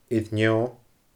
air neo /əR ˈNʲɔ/